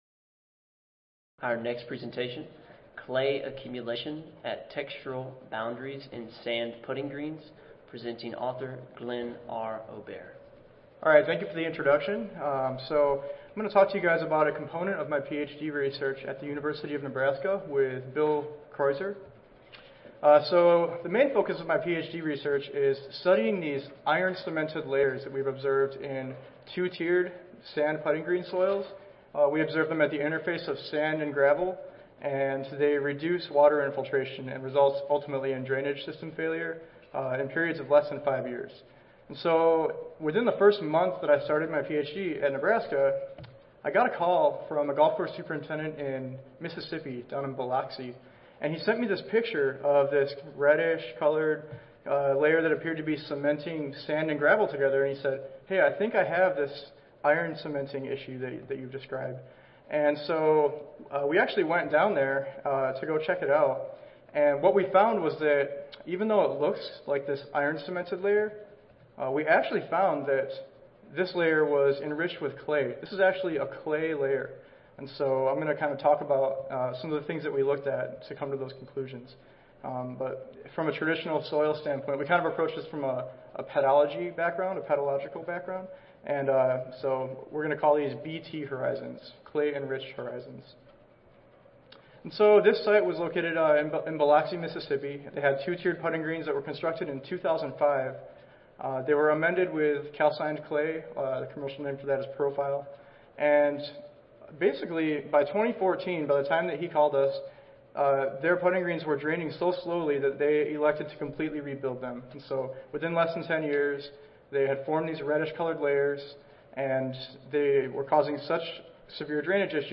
Lincoln Audio File Recorded Presentation